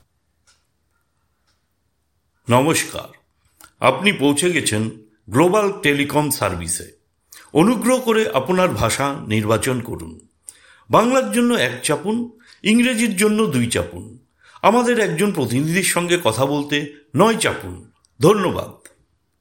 IVR / Phone System – Bengali (Professional & Clear)